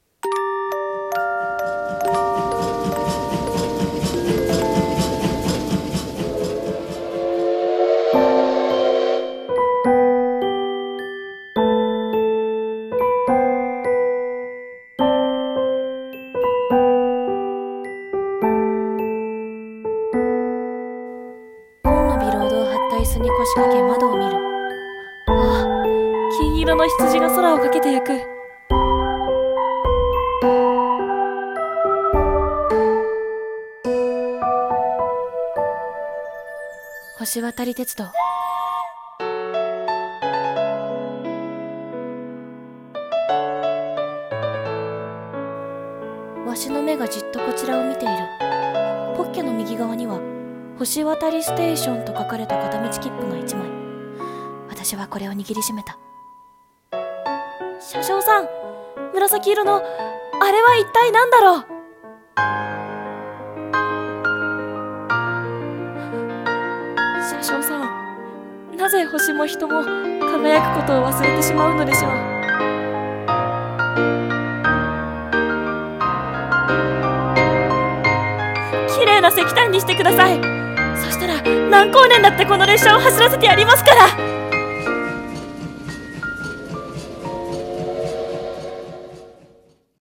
CM風声劇「星渡り鉄道」